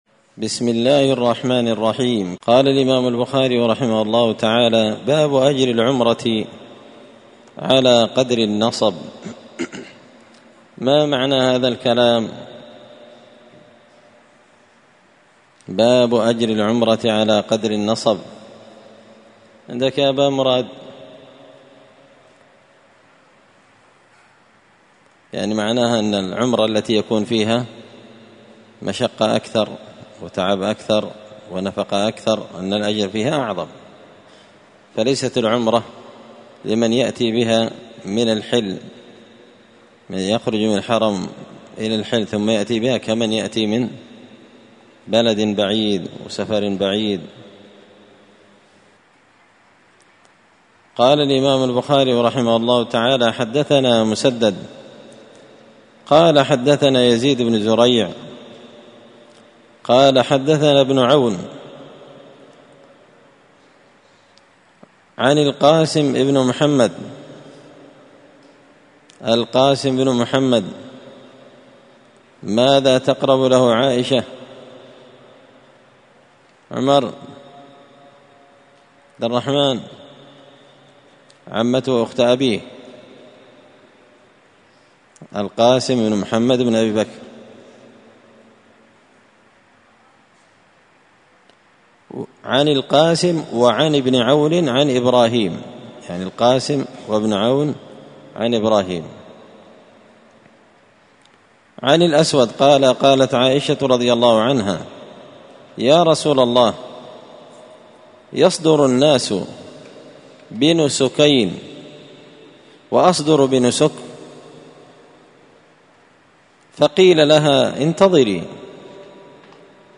كتاب العمرة من شرح صحيح البخاري- الدرس 8 باب أجر العمرة على قدر النصب